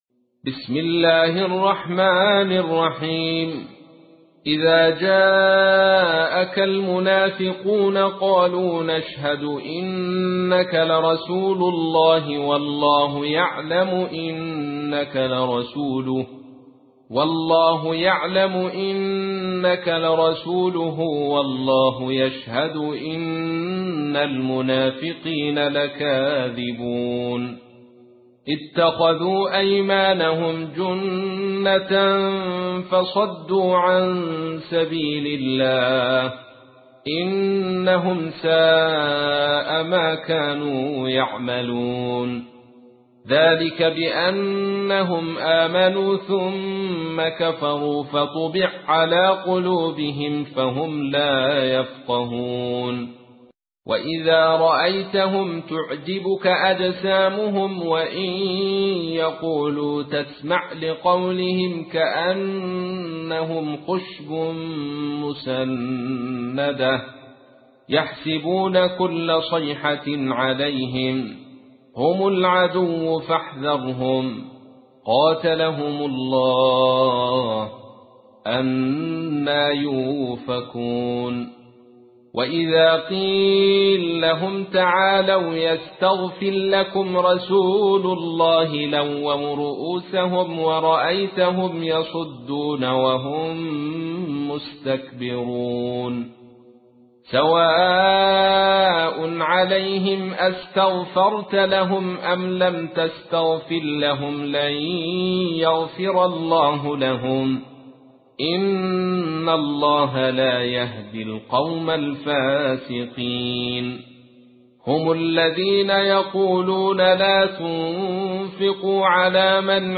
تحميل : 63. سورة المنافقون / القارئ عبد الرشيد صوفي / القرآن الكريم / موقع يا حسين